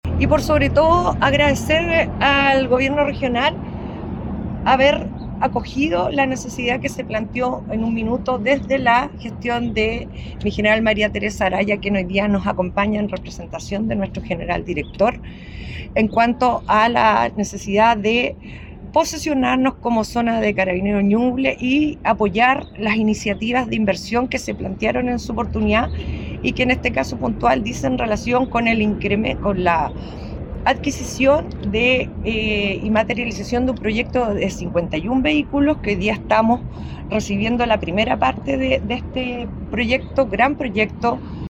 La jefa de zona de Carabineros de Ñuble, general Loreto Osses, valoró la entrega como parte de un esfuerzo sostenido por dotar a la nueva zona policial de mayores capacidades operativas.